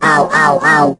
rick_hurt_03.ogg